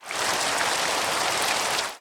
rain1.ogg